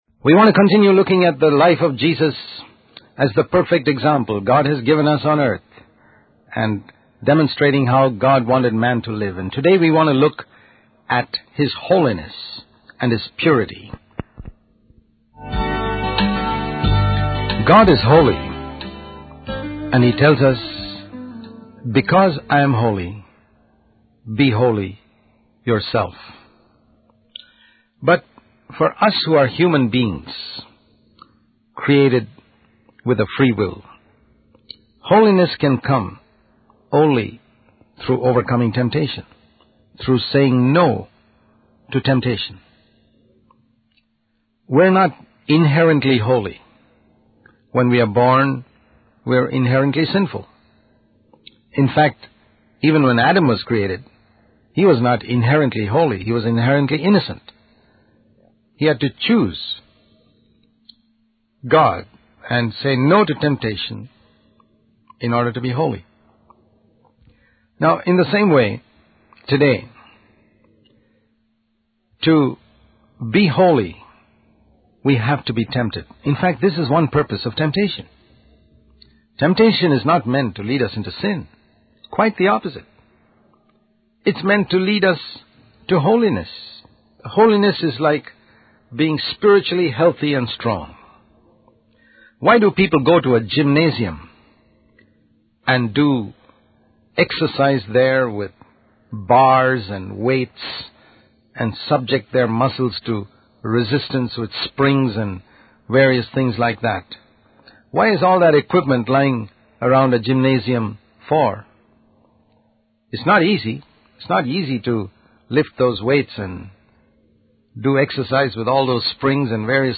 In this sermon, the speaker emphasizes the importance of holiness and overcoming temptation.